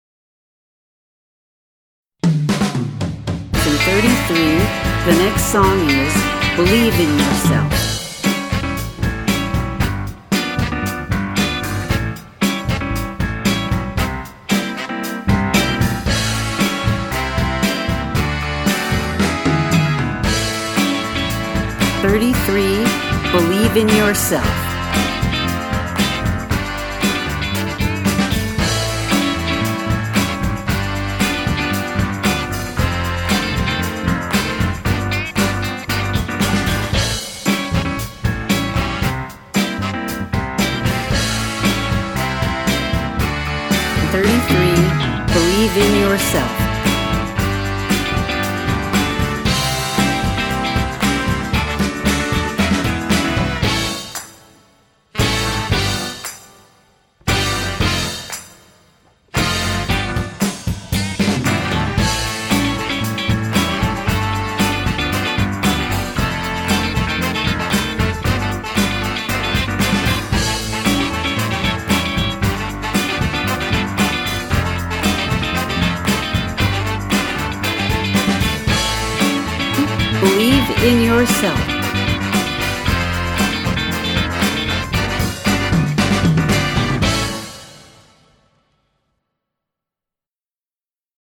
• Funk
• Big Band